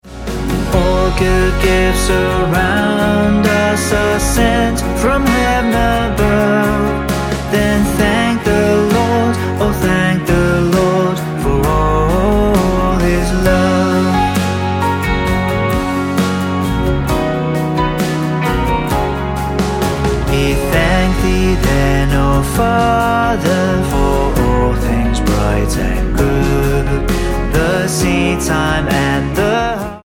F#